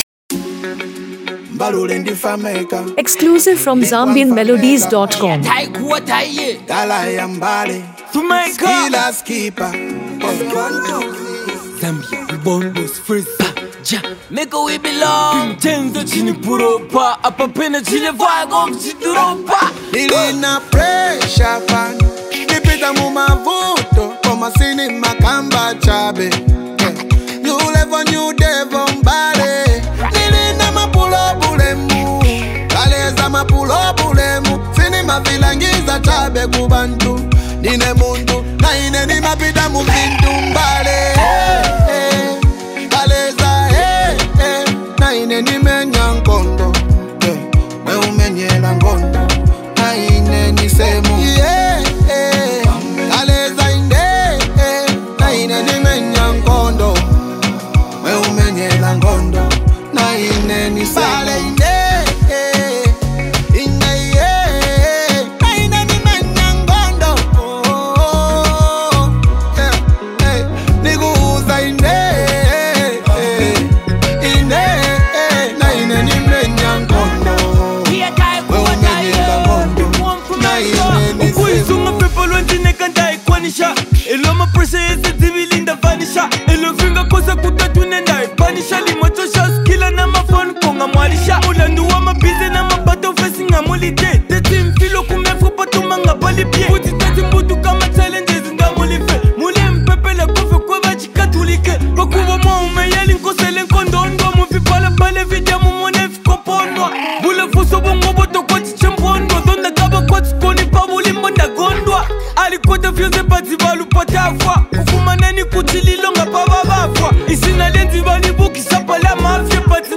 As an Afro-fusion track